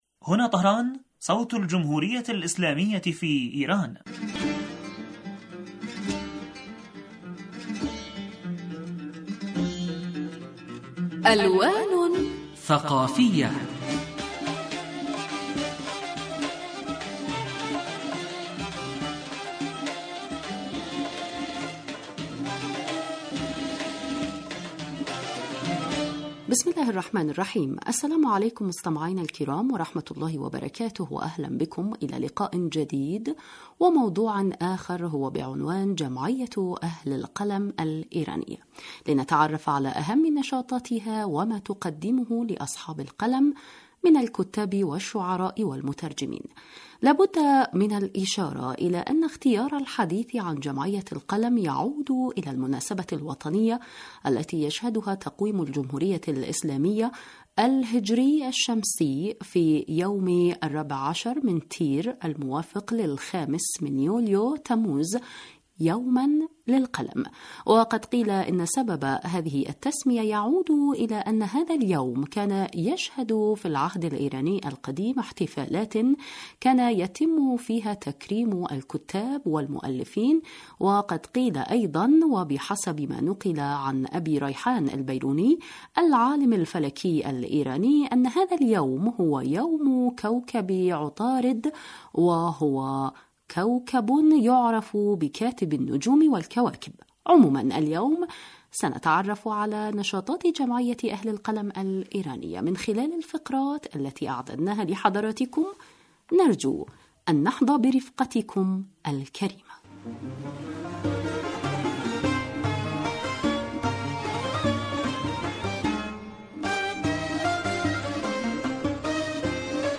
لقاء ثقافي وأدبي یجمعنا بکم أسبوعیا عبر تجوال ممتع في أروقة الحقول الثقافیة والحضاریة والأدبیة لإيران الإسلامية ویشارکنا فیه عدد من الخبراء وذووي الاختصاص في الشأن الثقافي الإيراني لإيضاح معالم الفن والأدب والثقافة والحضارة في إیران